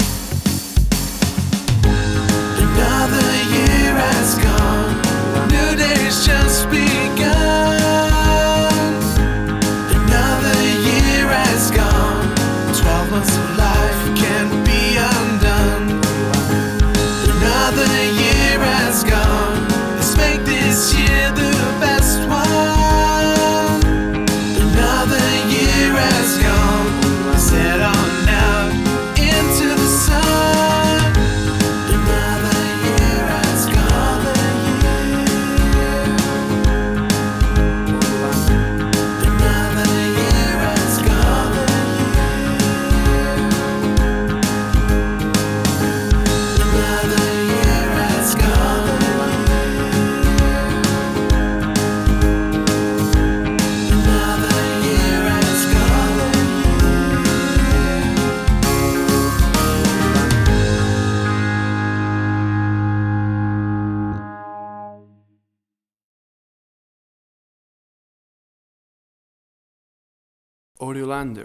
A cool vocal pop song all about new years and new years eve!
Upbeat and Uptempo vocal music with full vocal productio
Tempo (BPM): 132